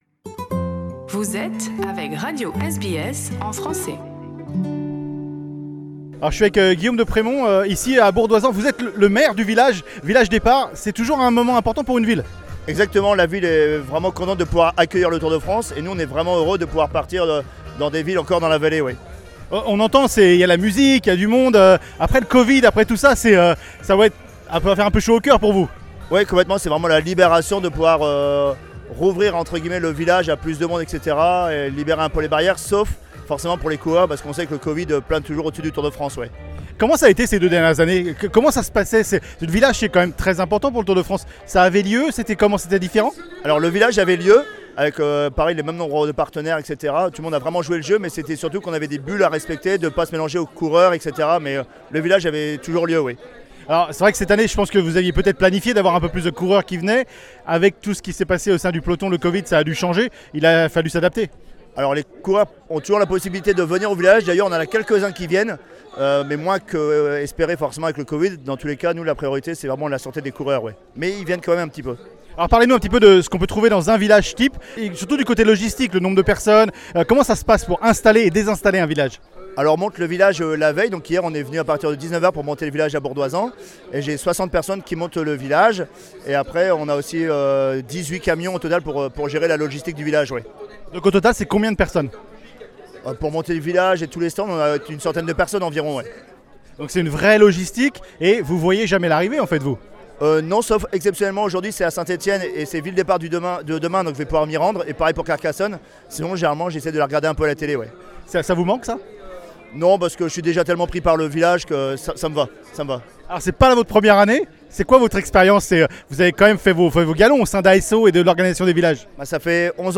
Rencontre avec Guillaume De Premont Maire du Village de Bourg-d'Oisans